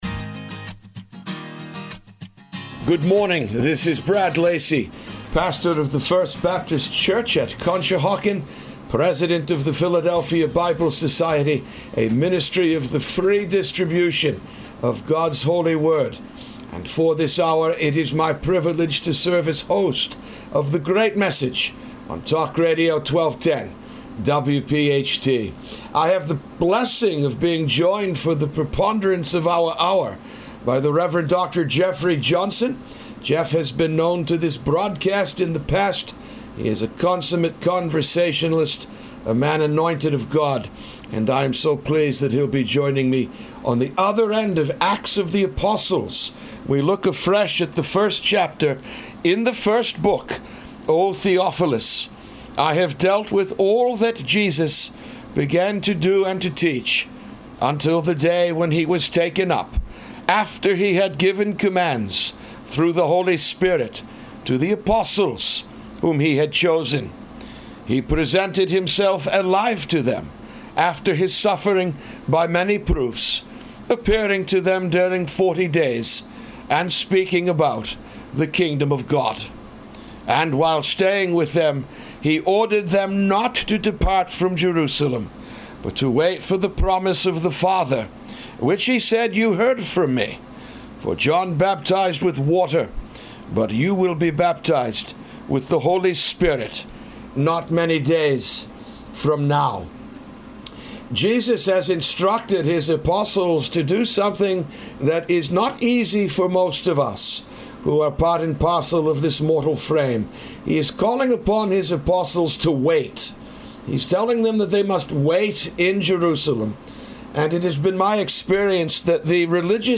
The Great Message A Conversation